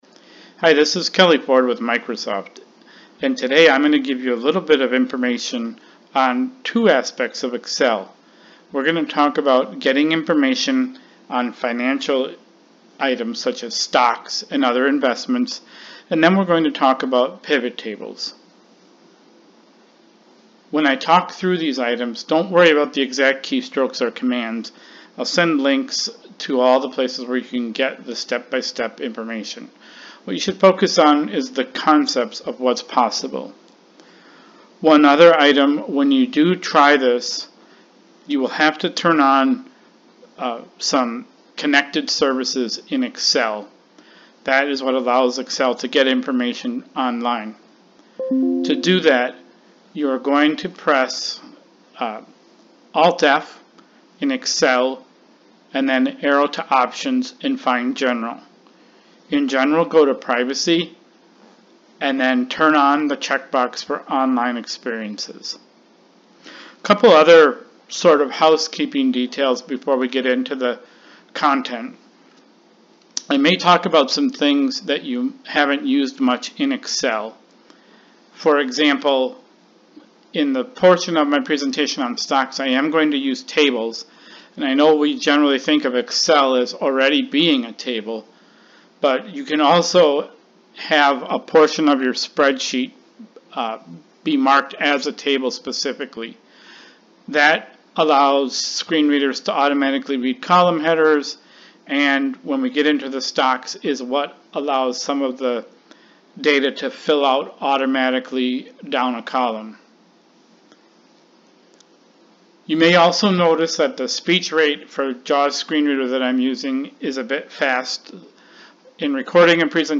Earlier this month I was part of a panel presentation at the American Council of the Blind’s summer convention.